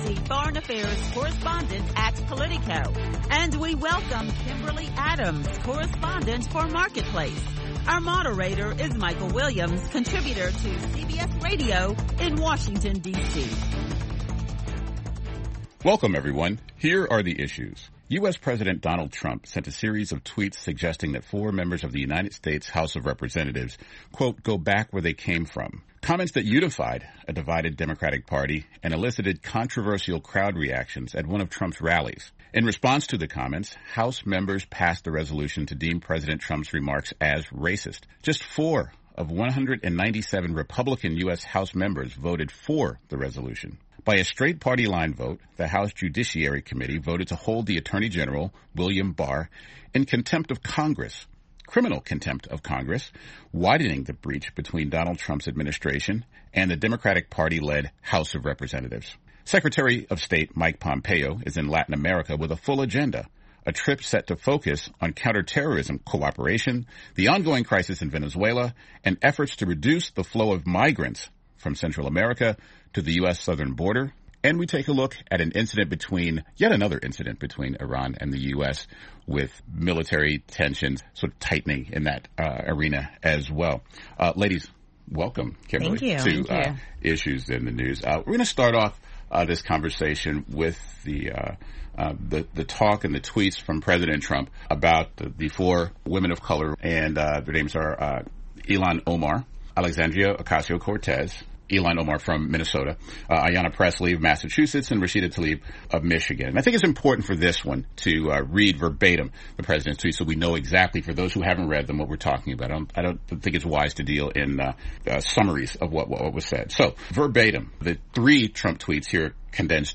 Listen to a panel of prominent Washington journalists as they deliberate the week's headlines including President Trump telling four members of Congress they could leave the country if they were unhappy and go back to the countries they came, a top U.S. diplomat visits Latin America for a regional security conference.